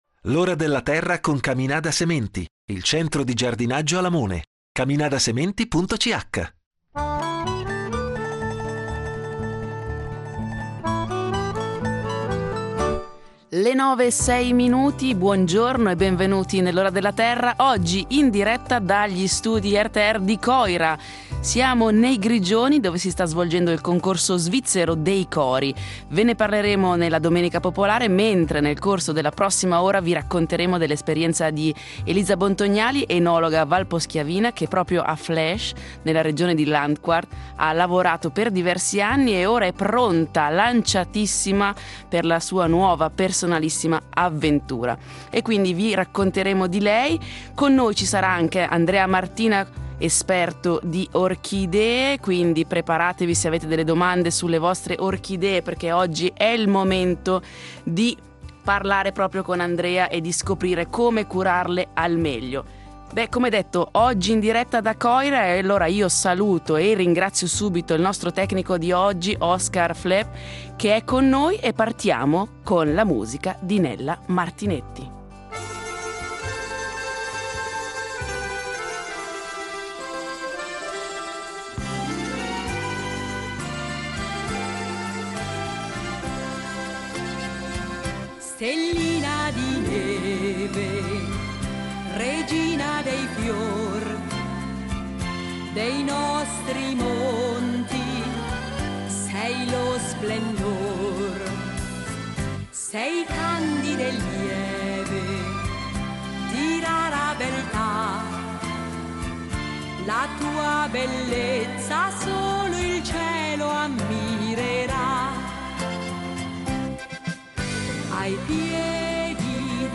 In diretta dagli studi di RTR di Coira